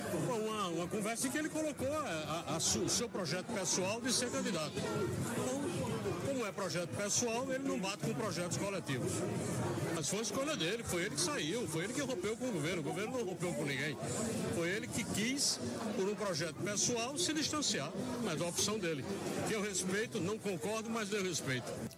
Durante a entrevista, João Azevêdo reforçou que a decisão de Cícero não está alinhada com os objetivos da gestão estadual:
O comentário de Azevêdo foi registrado pelo programa Correio Debate, da 98 FM, de João Pessoa, nesta segunda-feira (08/09):